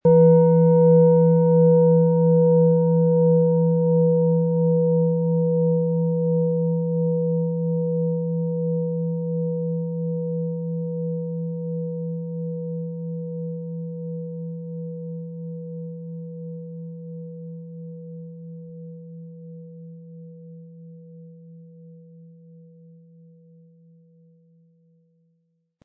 Hopi Herzton
Mit viel Liebe und Sorgfalt in Handarbeit erstellte Klangschale.
• Tiefster Ton: Mond
Sie möchten den schönen Klang dieser Schale hören? Spielen Sie bitte den Originalklang im Sound-Player - Jetzt reinhören ab.
MaterialBronze